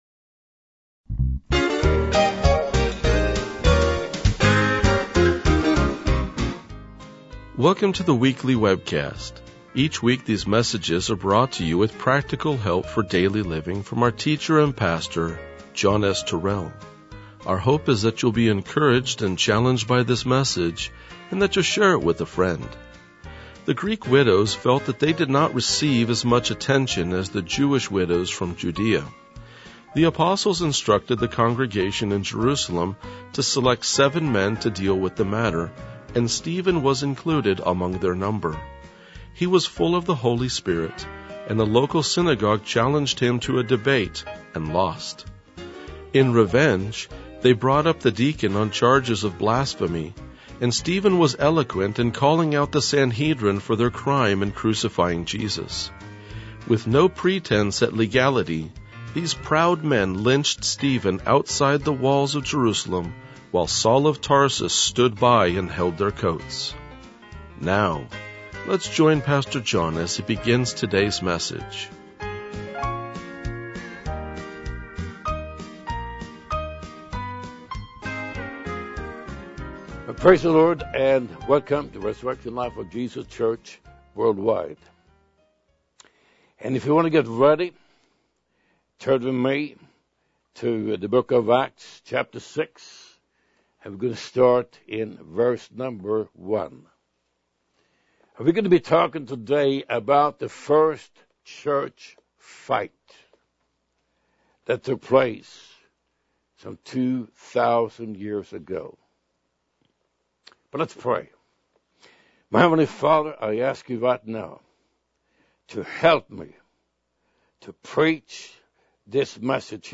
RLJ-2004-Sermon.mp3